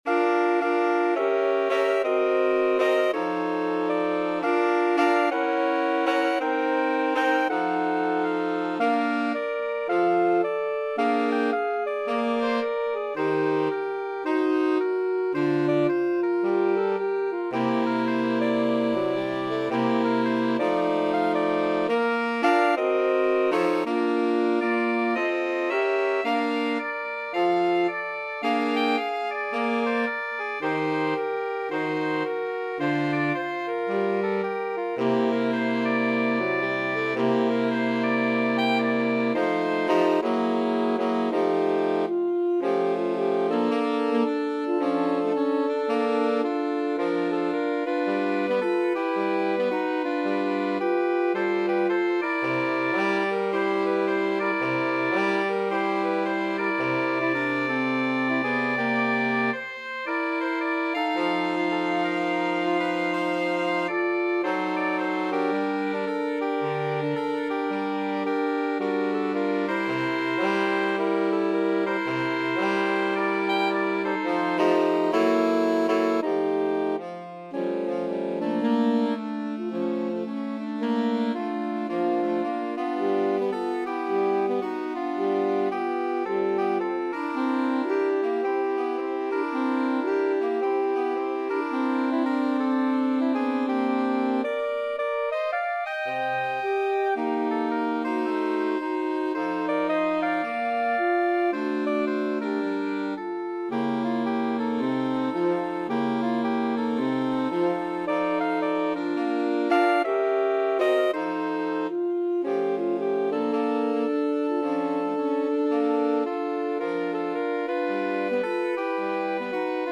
2/2 (View more 2/2 Music)
~ = 110 Moderate swing
Saxophone Quartet  (View more Easy Saxophone Quartet Music)